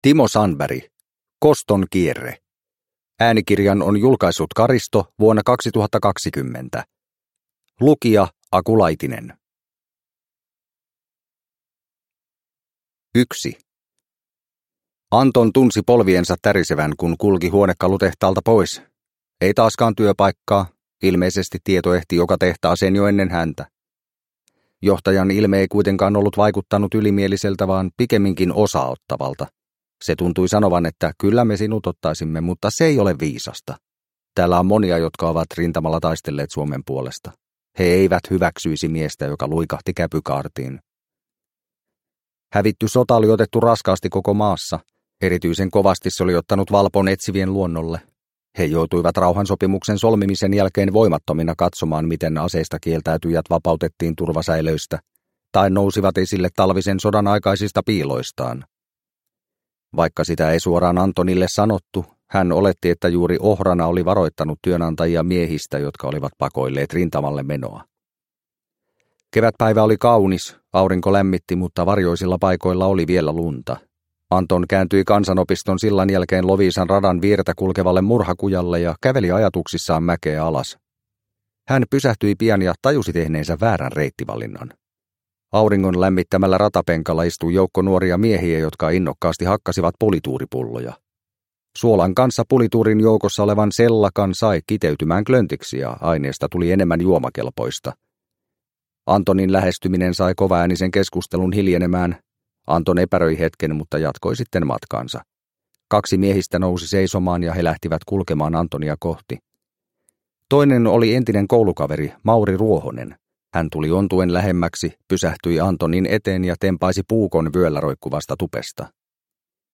Kostonkierre – Ljudbok – Laddas ner